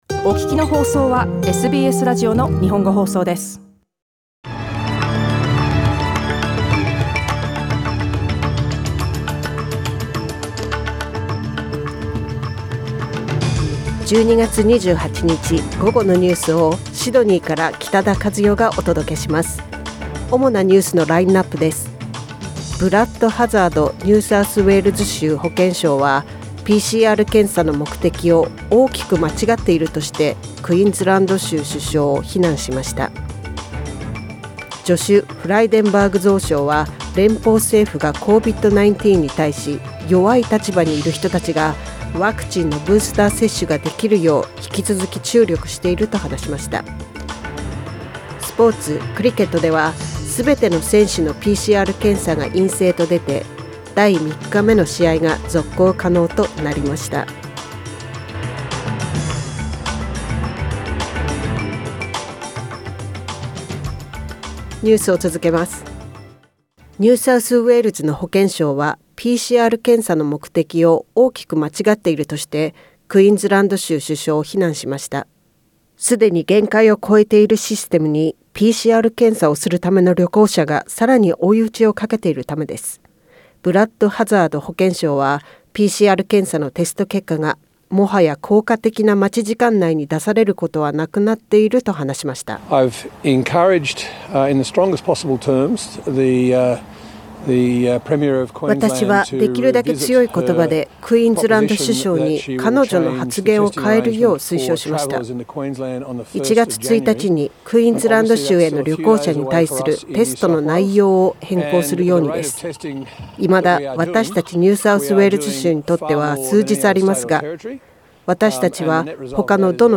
12月28日火曜日午後のニュースです。